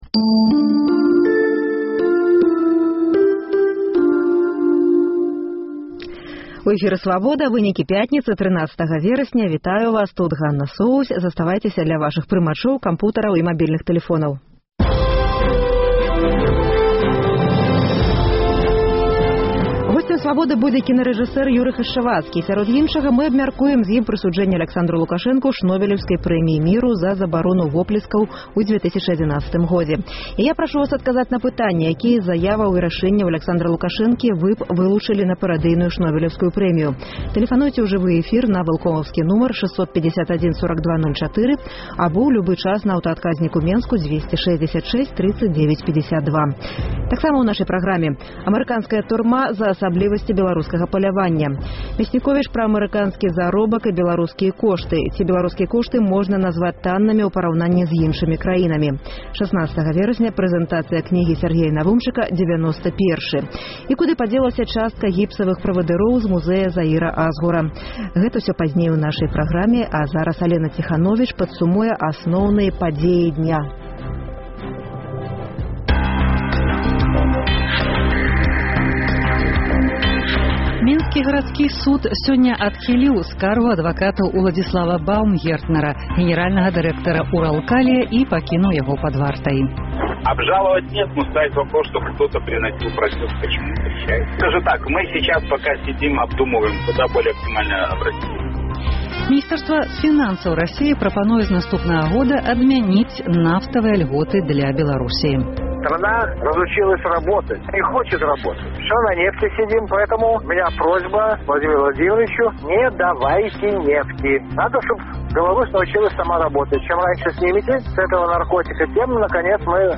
Госьцем Свабоды будзе кінарэжысэр Юры Хашчавацкі і сярод іншага мы абмяркуем зь ім прысуджэньне Аляксандру Лукашэнку Шнобэлеўскай прэміі міру за забарону воплескаў у 2011 годзе. Якія з заяваў і рашэньняў Аляксандра Лукашэнкі вы б вылучылі на парадыйную Шнобэлеўскую прэмію?